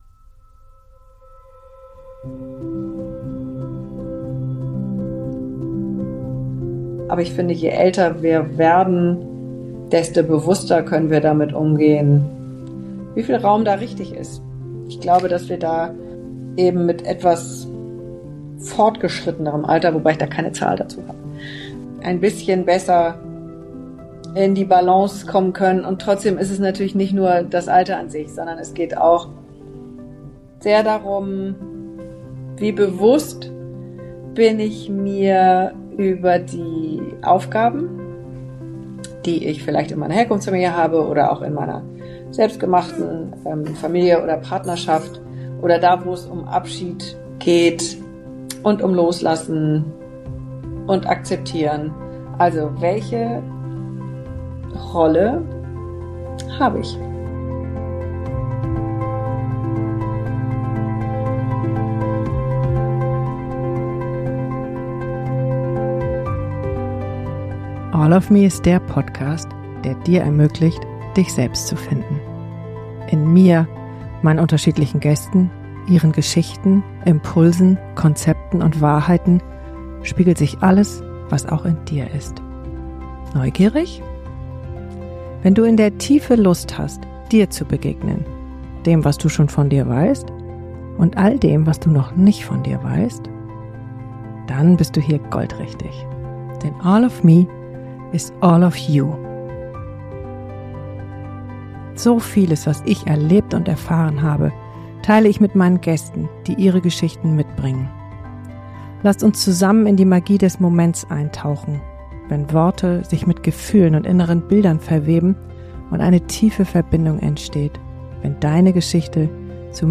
Also, Zeit für eine Solofolge.